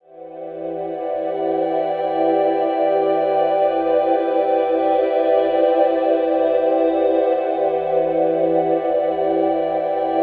PENSIVE   -R.wav